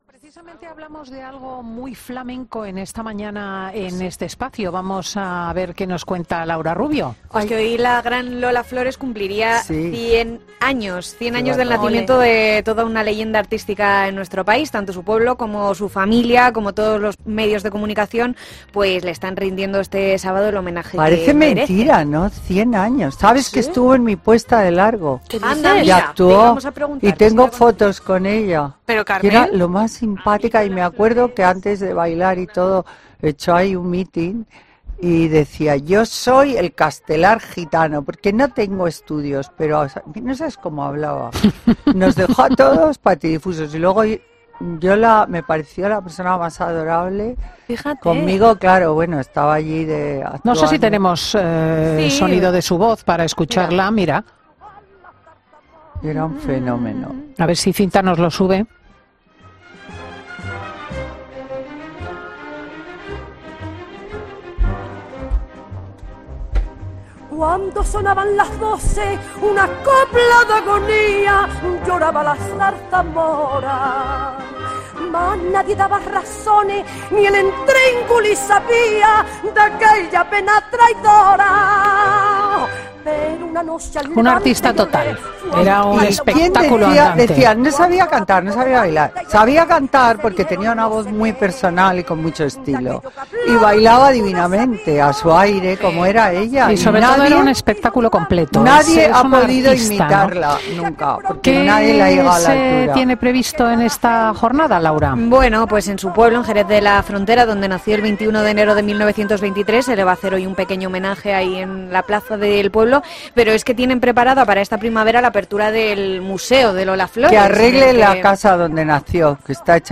Poca broma, porque en esta tertulia teníamos a nuestra socialité preferida: Carmen Lomana.